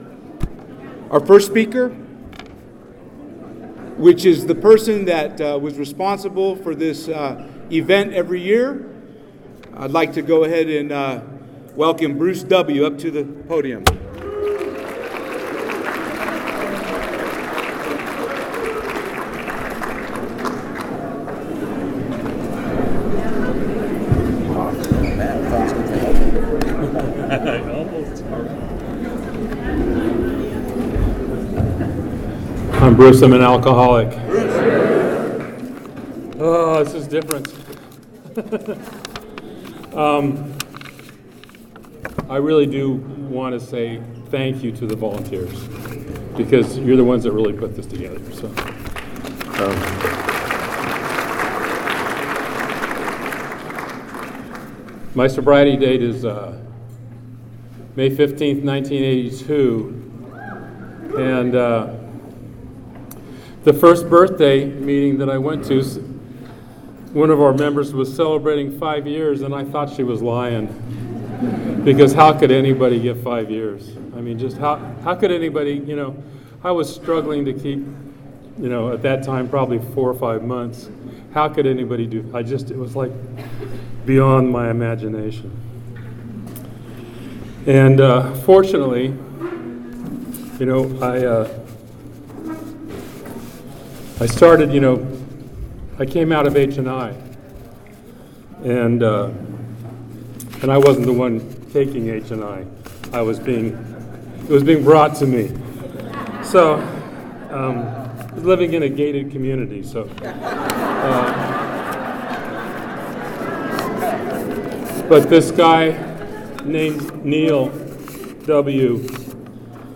18th Annual Take Your Sponsor To Dinner